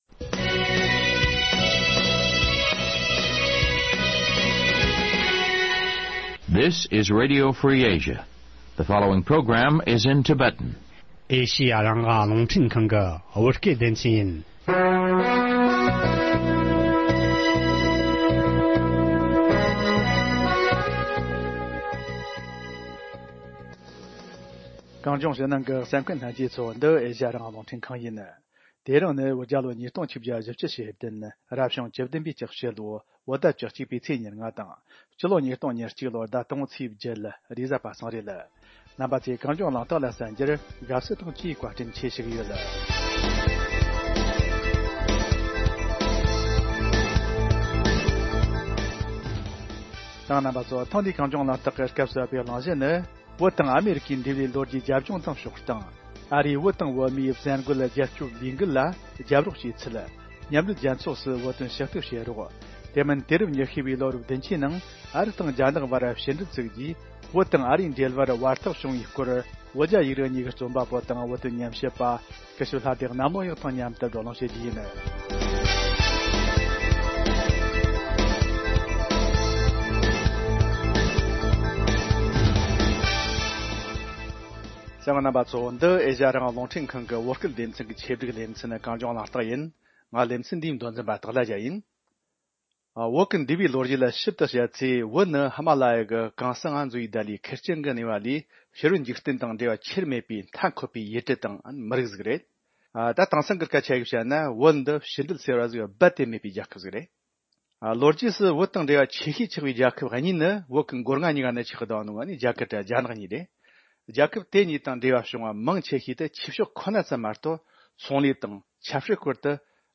བགྲོ་གླེང་ཞུས་པར་གསན་རོགས་ཞུ།།